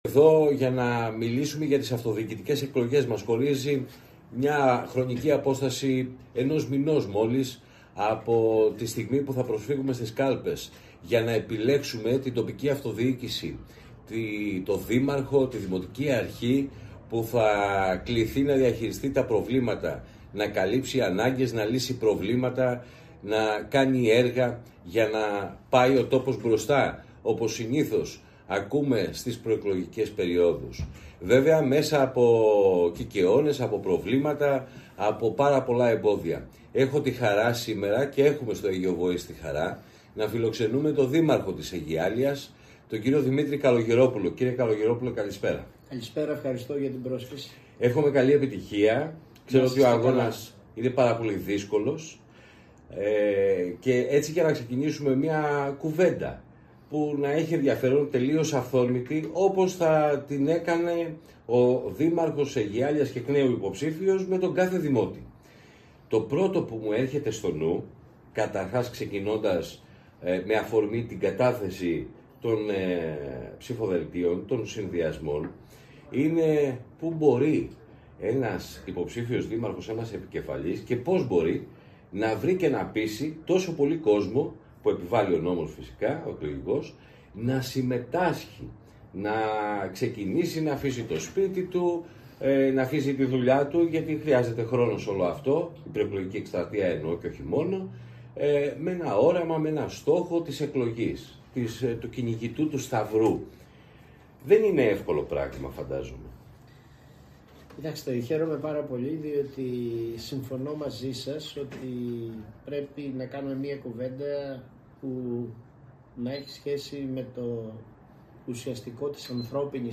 Ακούστε από το 27:01 τα λόγια του εκλιπόντος Δημάρχου Αιγιαλείας